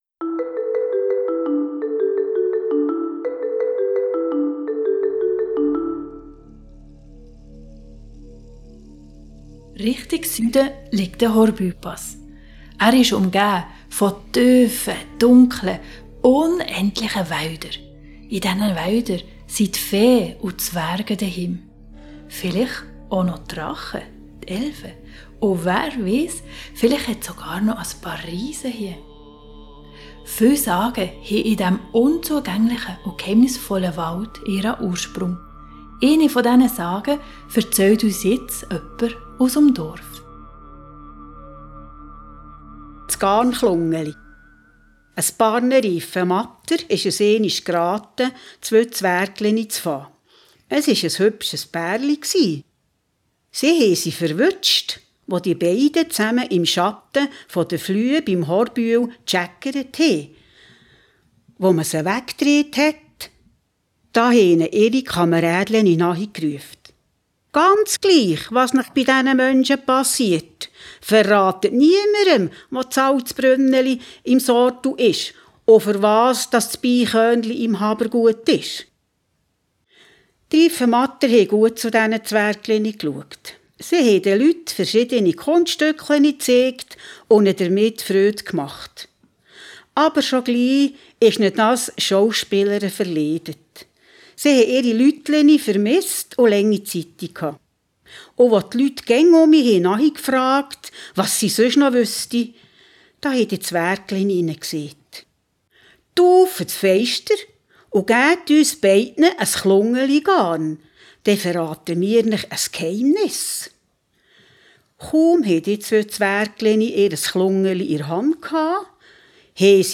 2_riffenmatt_audiodatei-der-fadenknaeuel_sagenroute-gantrisch_c_bern-welcome.mp3